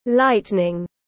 Lightning /ˈlaɪt.nɪŋ/